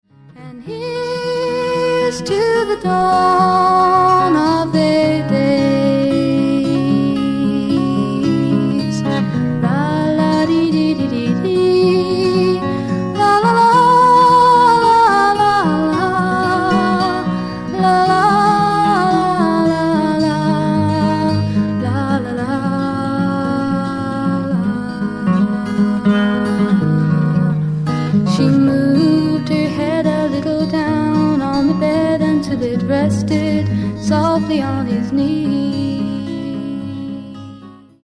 FEMALE FOLK / PSYCHEDEIC POP